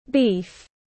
Thịt bò tiếng anh gọi là beef, phiên âm tiếng anh đọc là /biːf/
Beef /biːf/